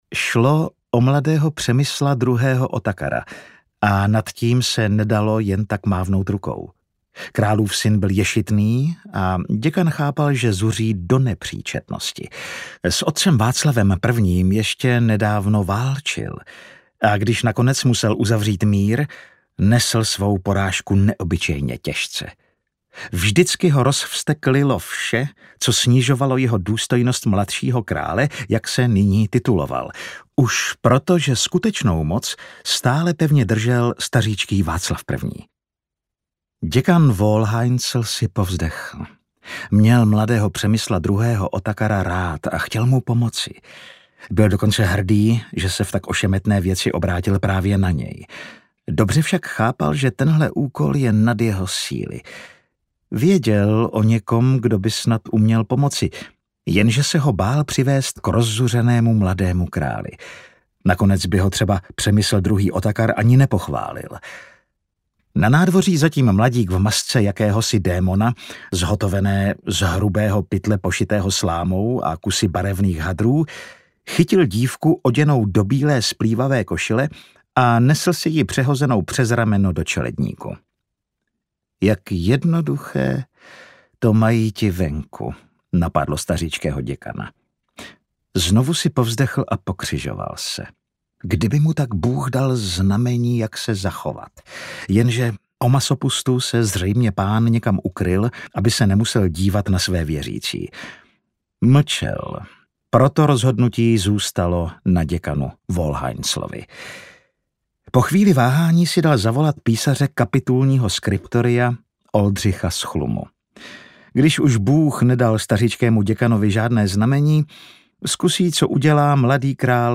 Masopustní maškary audiokniha
Ukázka z knihy
• InterpretAleš Procházka